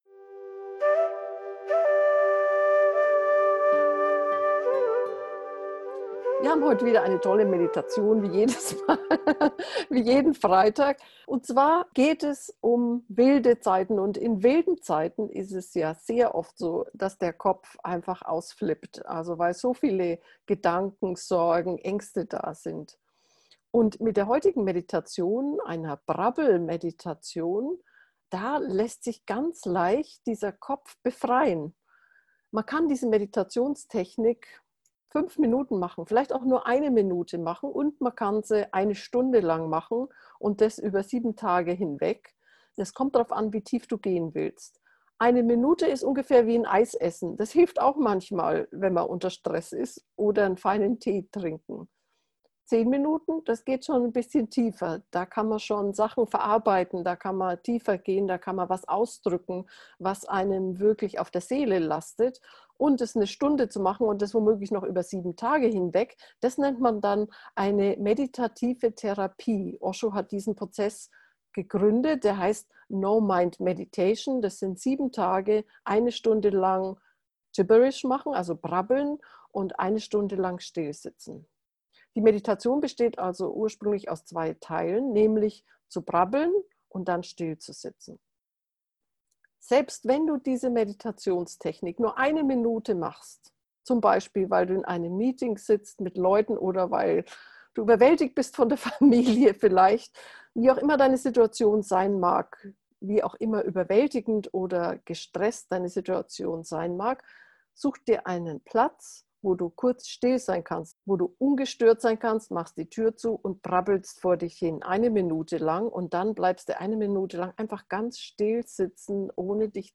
kopf-befreien-brabbeln-gefuehrte-meditation